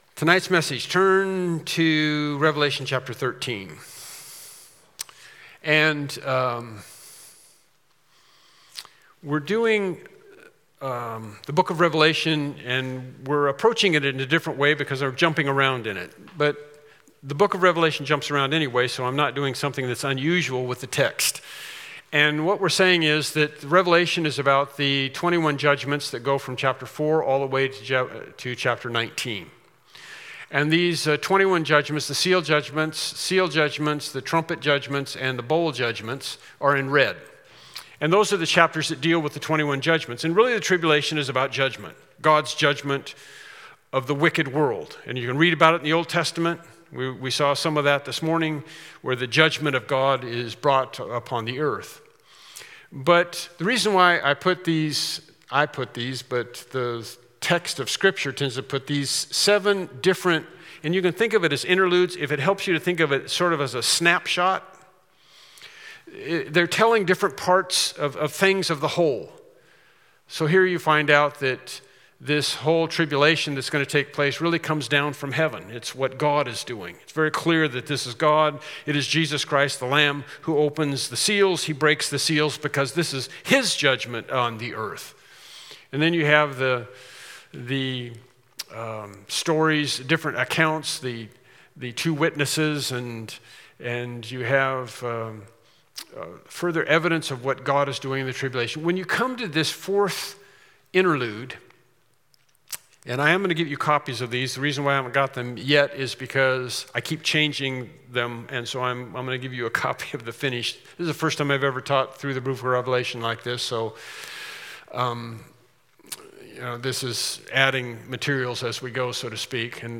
Service Type: Evening Worship Service